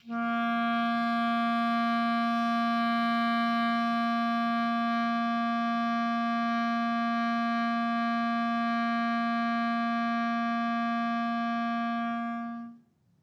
DCClar_susLong_A#2_v3_rr1_sum.wav